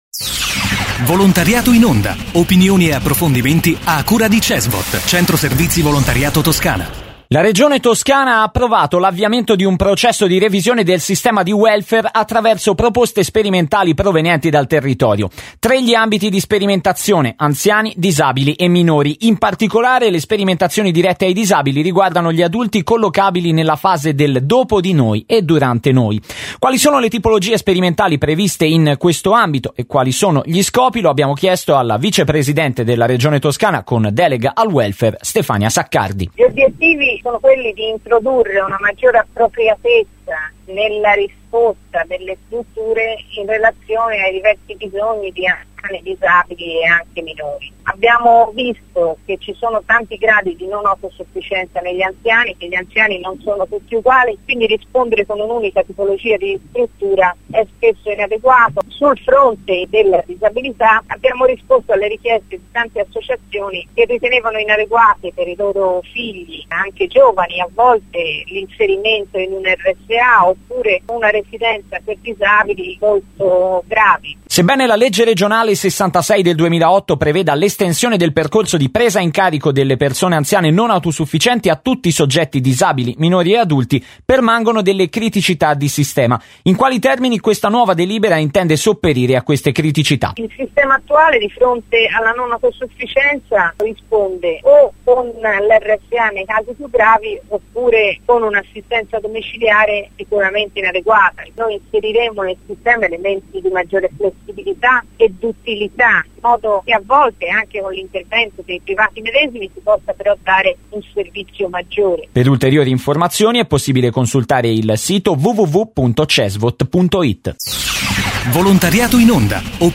Intervista a Stefania Saccardi, vicepresidente della Regione Toscana